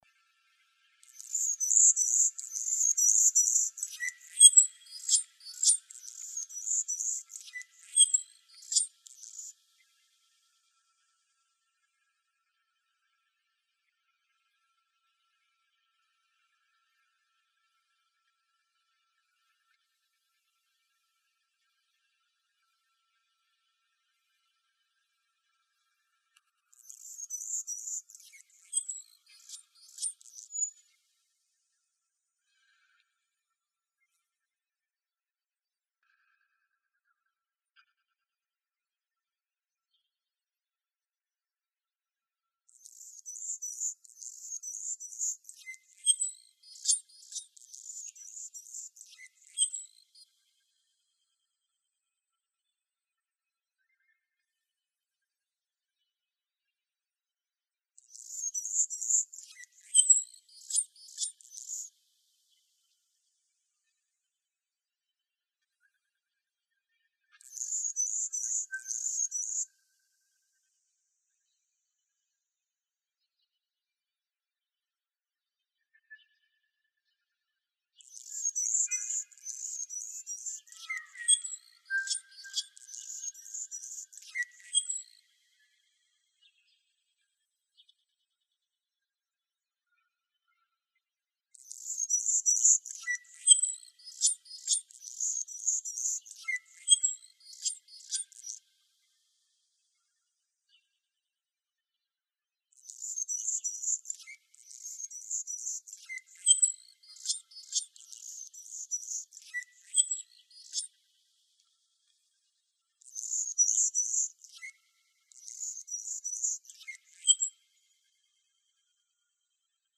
Hummingbird.wav